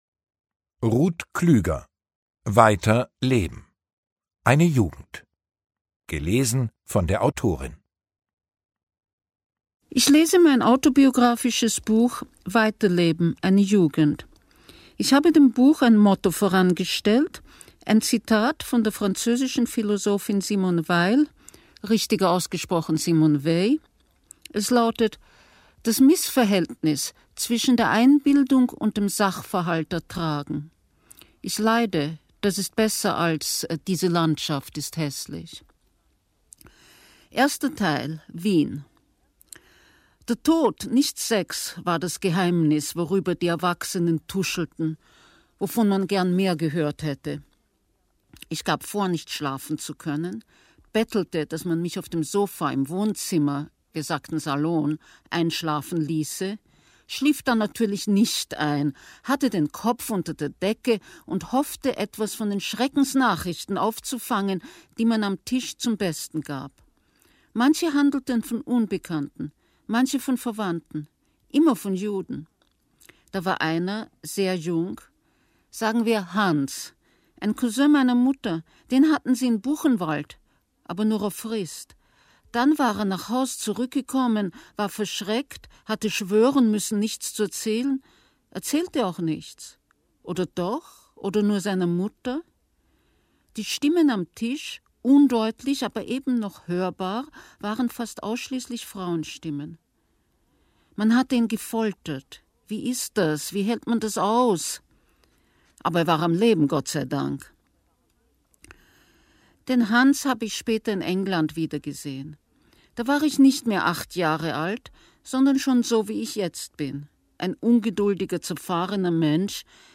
Hörbuch: weiter leben.